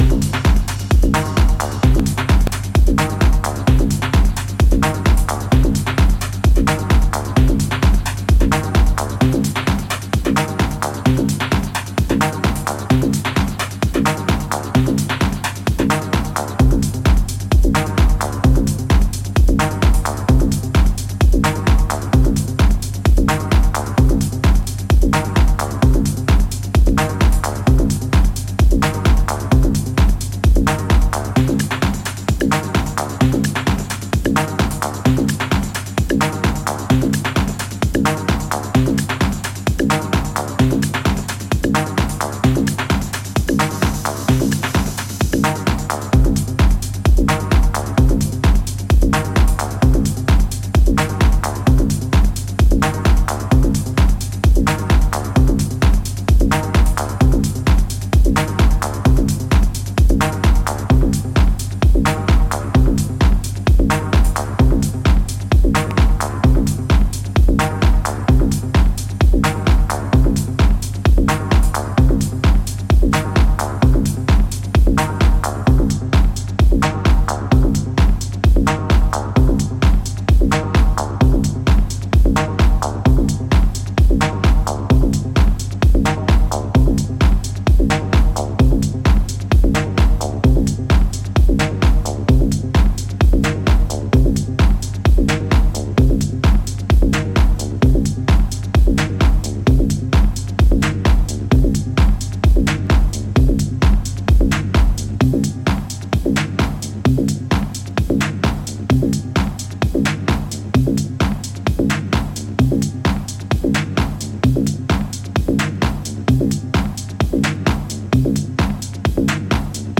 Old schoolish techno tracks
Techno Acid